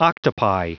Prononciation du mot octopi en anglais (fichier audio)
Prononciation du mot : octopi